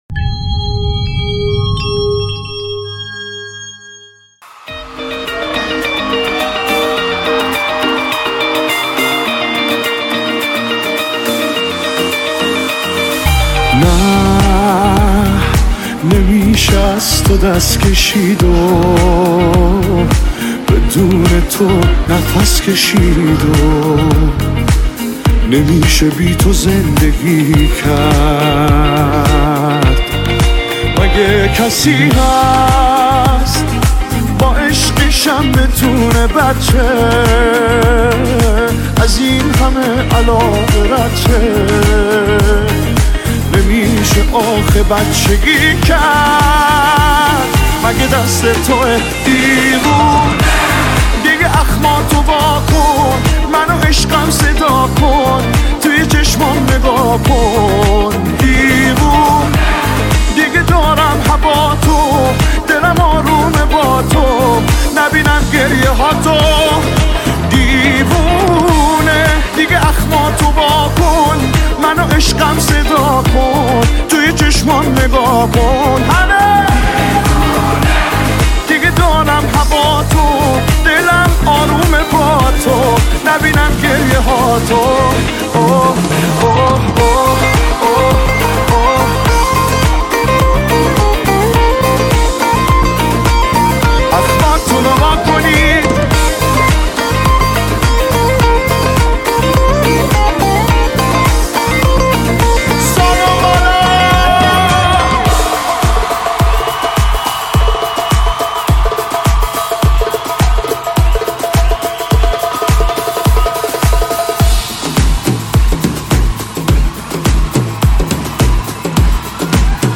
(اجرای زنده)
(Live)